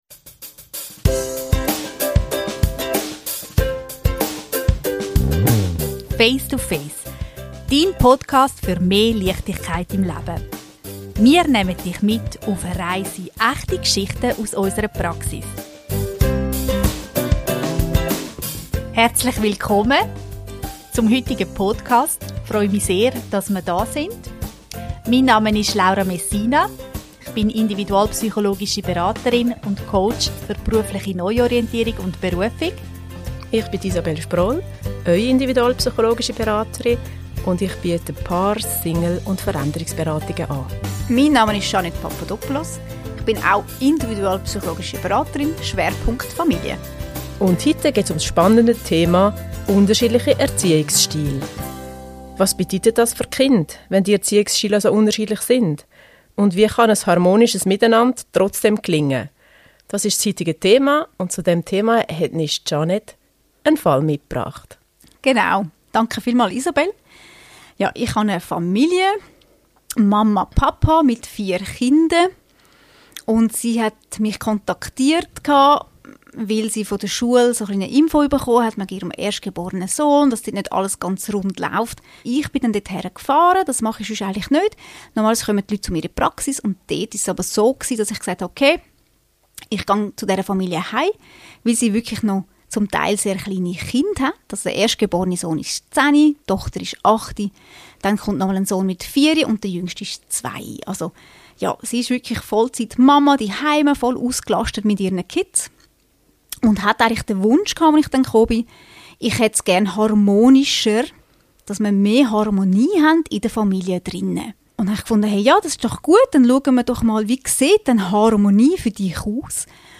In dieser Folge sprechen die drei individualpsychologischen Beraterinnen